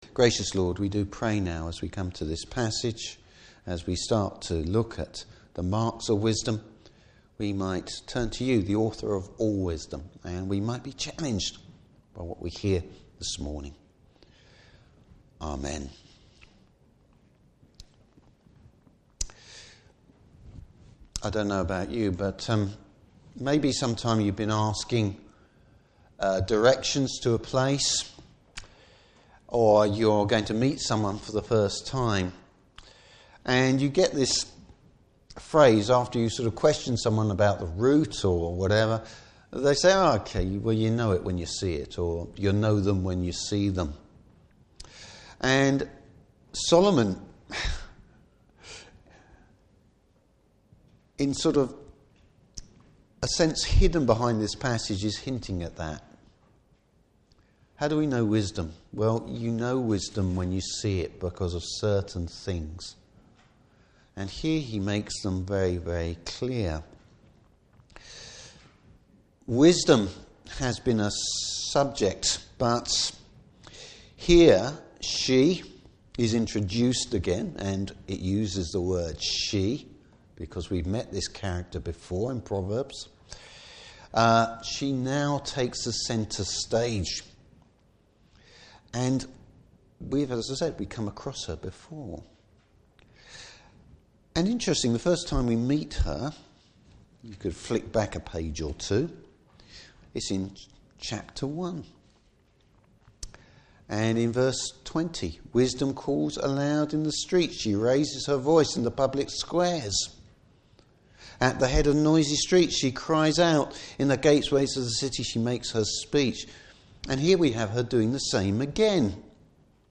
Service Type: Morning Service Finding and following the wisdom of the Lord.